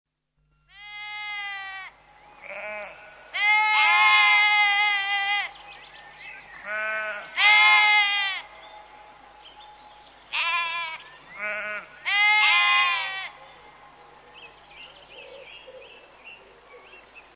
listen carefully they are definitely saying 'Blair'....'Blair'.... 'Blair'.
sheep.mp3